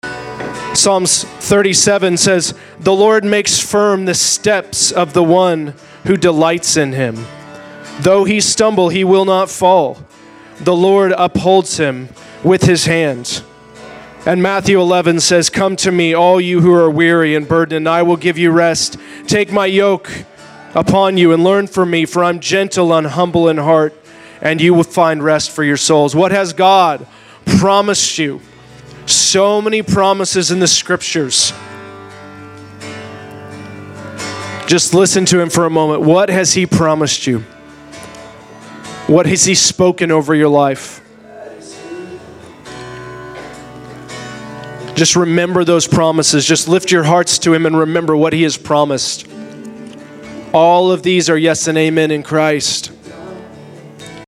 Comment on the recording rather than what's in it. A Word During Worship